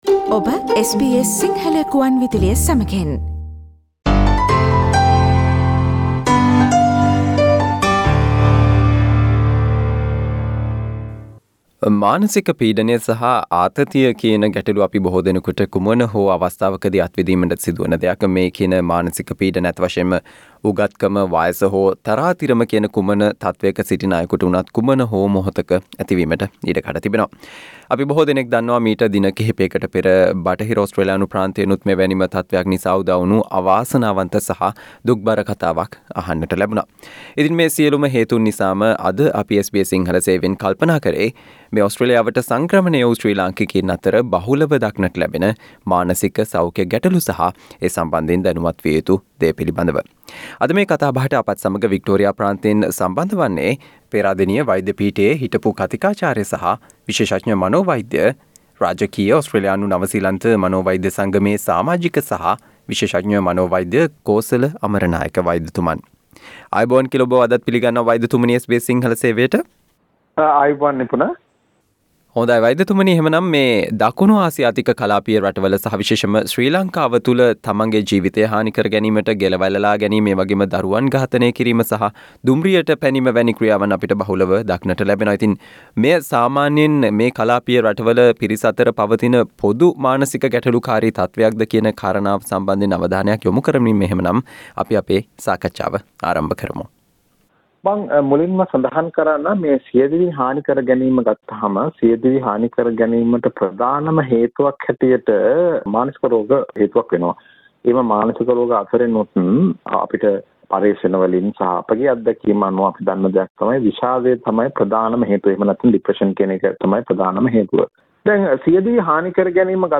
SBS Sinhala discussion on Things to know about mental health problems you may face in Australia